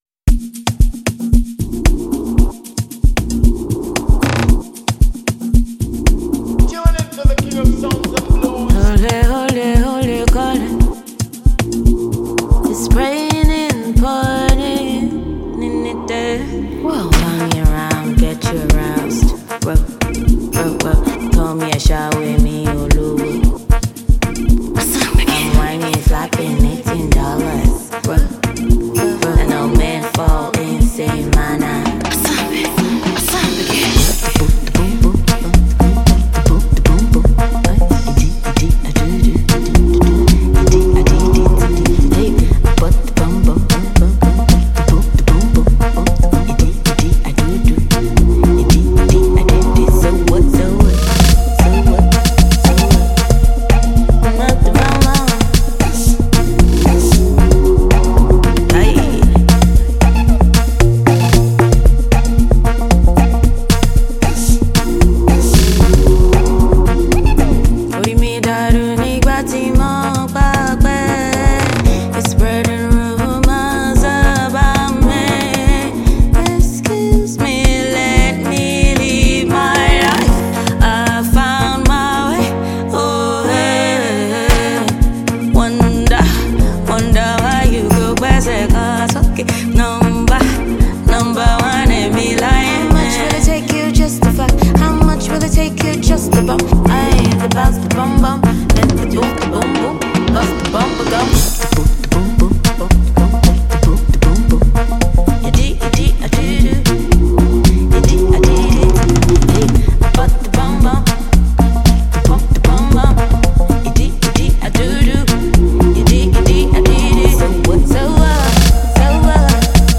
Talented Afro singer
featuring the assistance of Talented vocalist